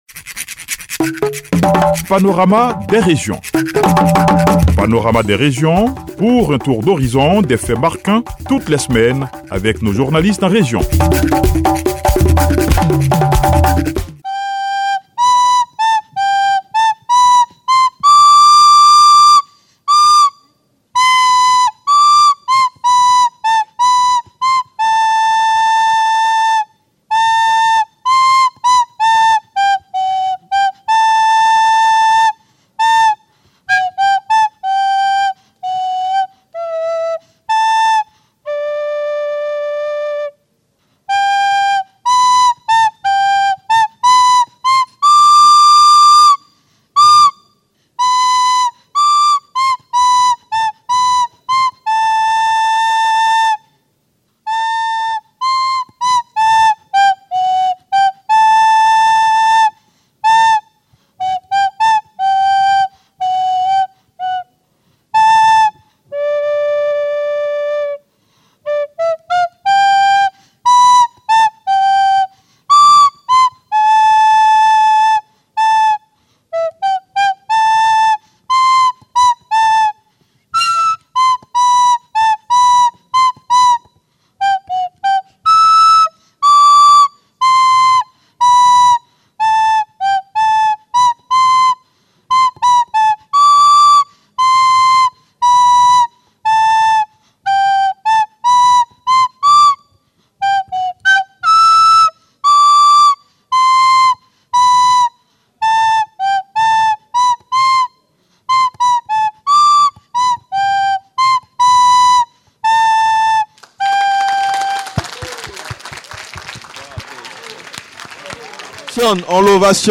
panorama-des-regions-les-cracks-en-live-a-yamoussoukro.mp3